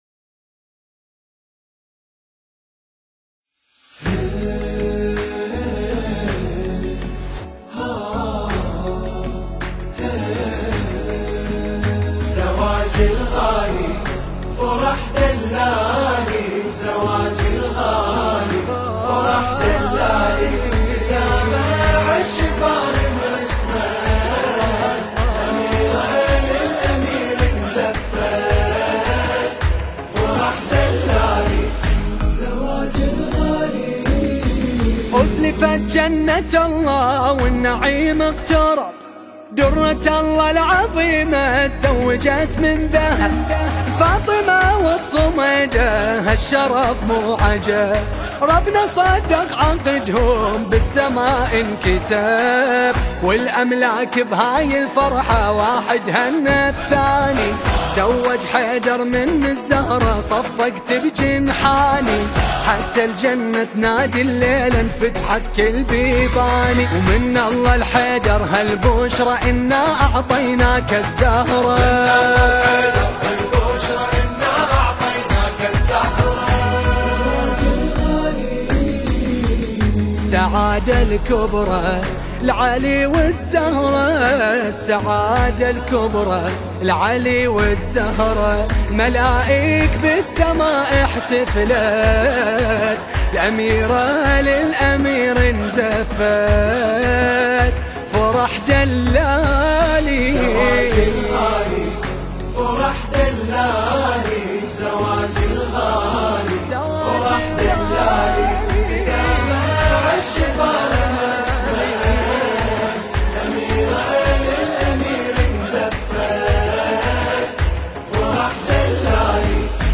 أداء و ألحان :
هندسة صوتية وتوزيع :